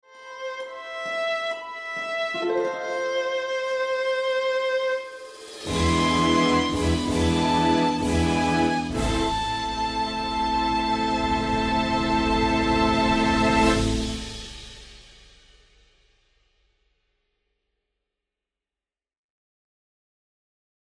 יפה מאוד, אהבתי את ההתפתחות עם הנבל.